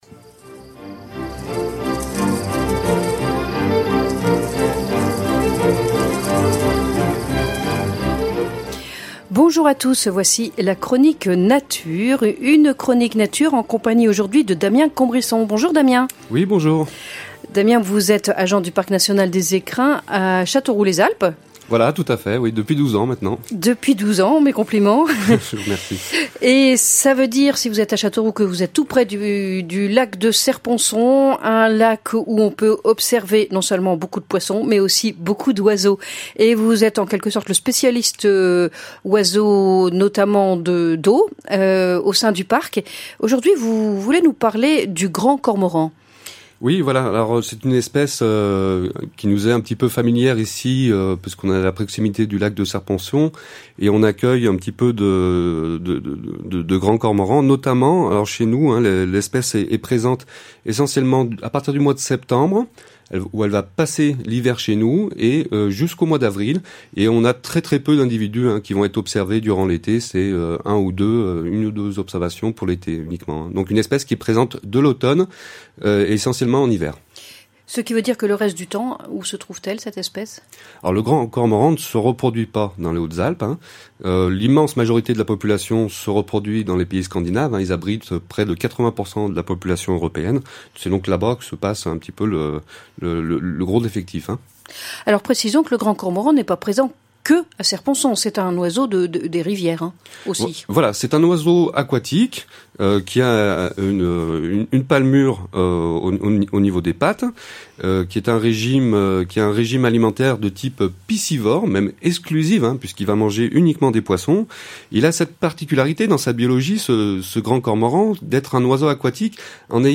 • Chronique nature